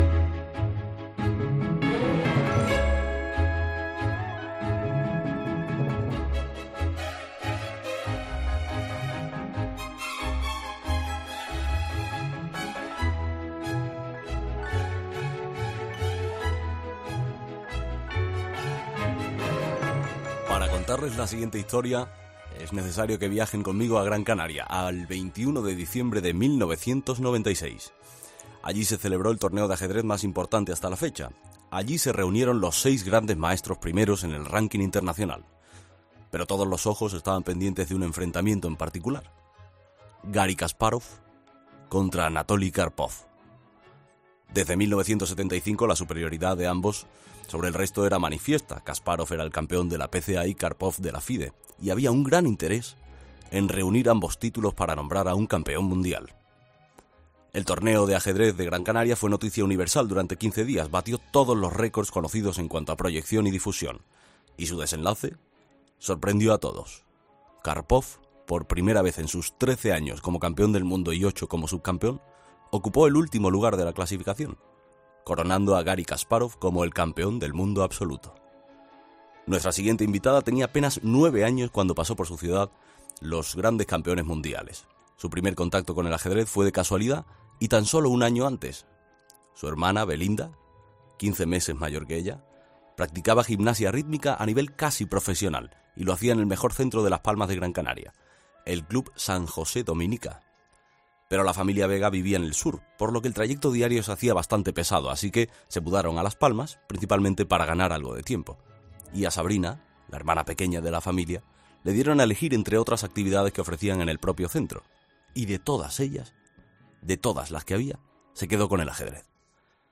Este miércoles, Sabrina Vega ha acudido a 'Herrera en COPE' para ser entrevistada.